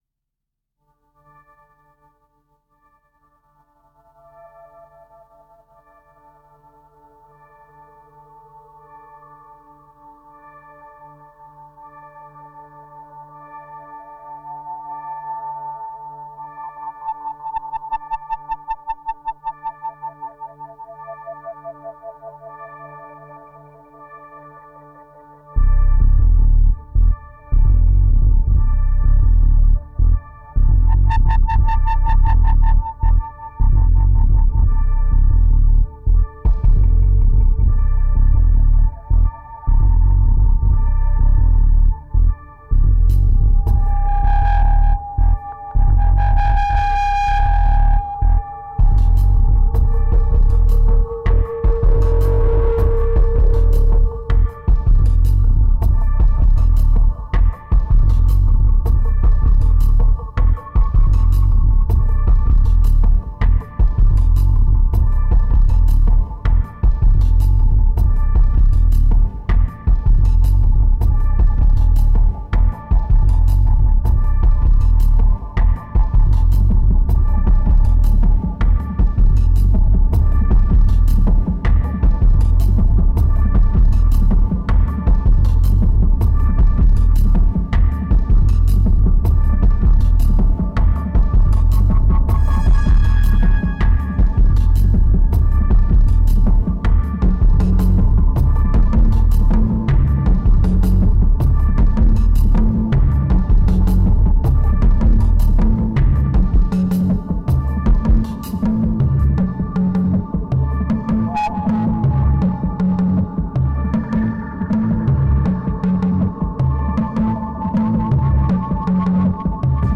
2236📈 - 48%🤔 - 79BPM🔊 - 2010-06-23📅 - 29🌟